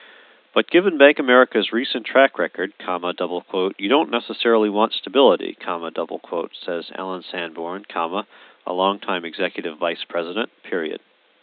Fullband Signal